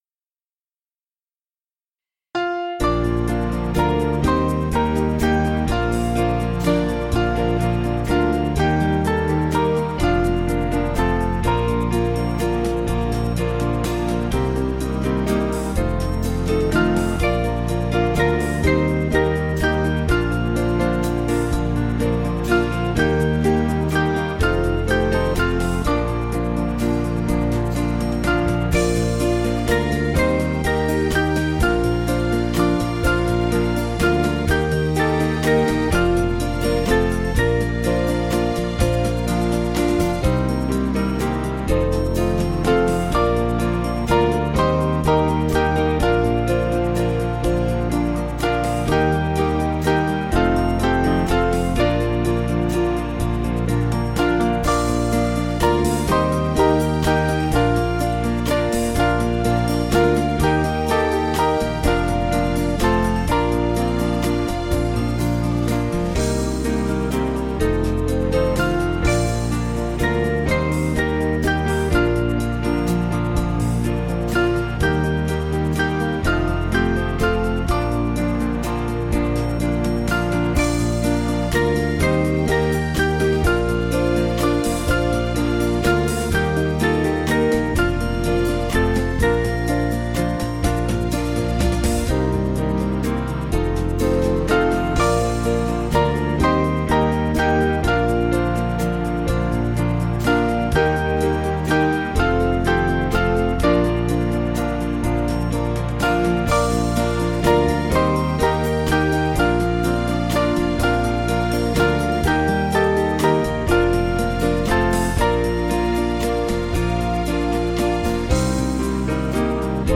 Small Band
(CM)   4/Bb 488.7kb